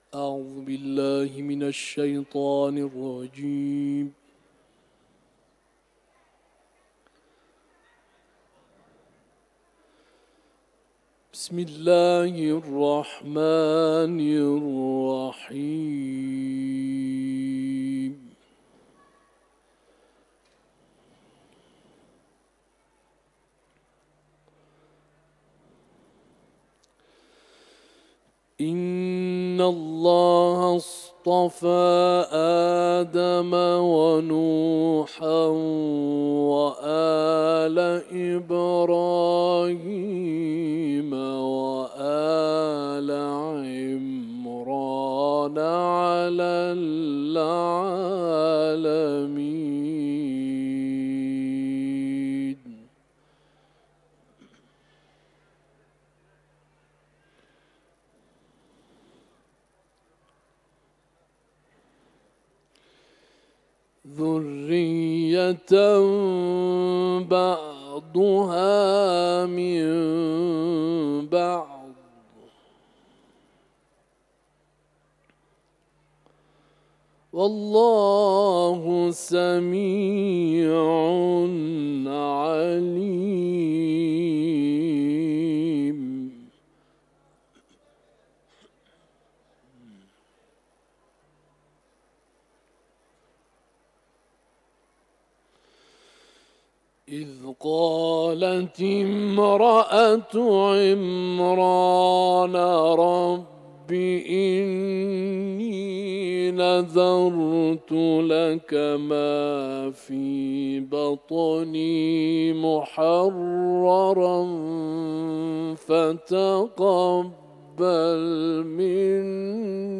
تلاوت
سوره آل عمران ، حرم مطهر رضوی